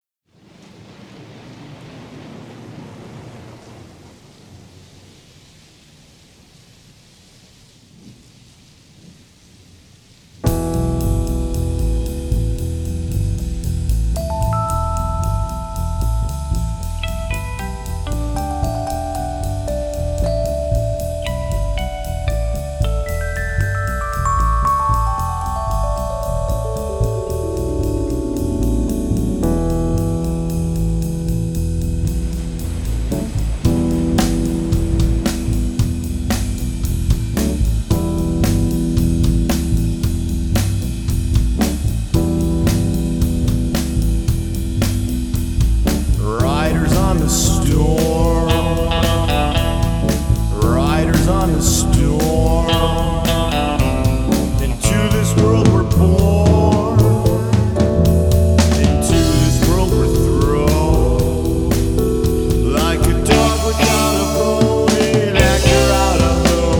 cover album